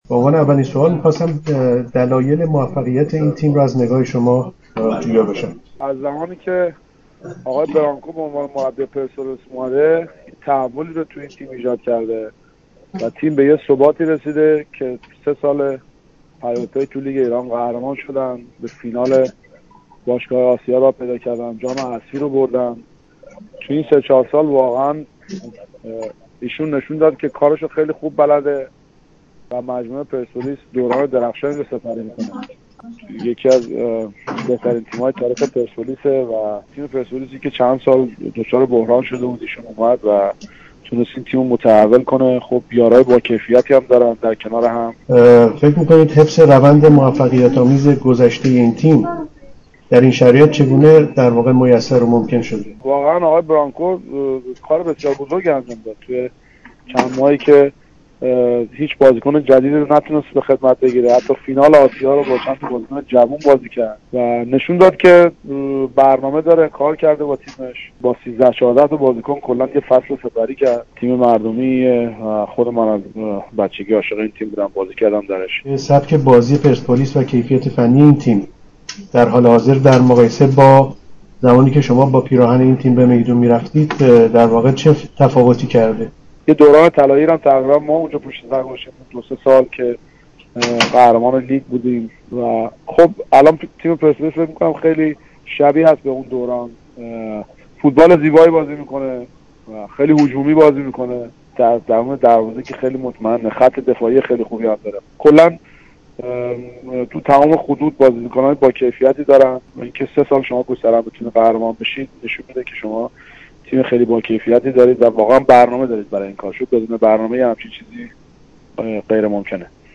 گفتگوی مهدی مهدوی کیا با یورونیوز درباره پرسپولیس و موفقیت هایش را می شنوید.